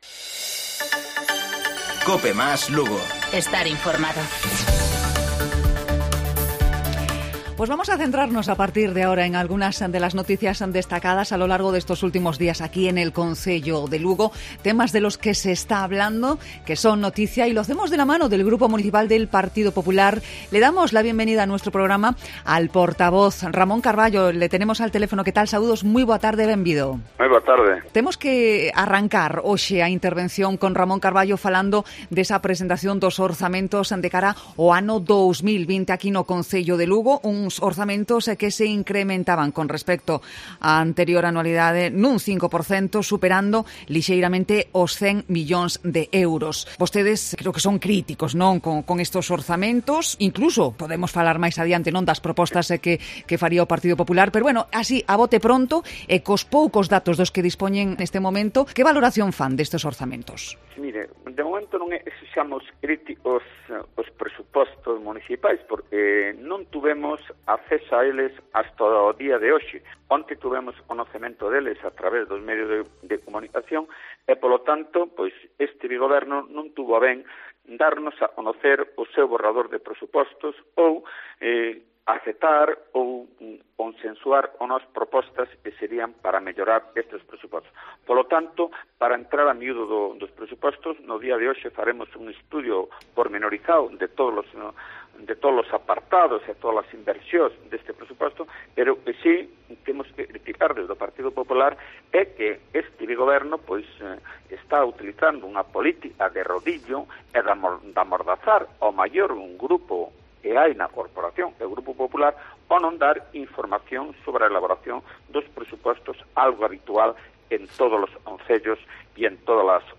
AUDIO: El portavoz de los populares, Ramón Carballo, afirma que presentarán una batería de propuestas que han recogido de los ciudadanos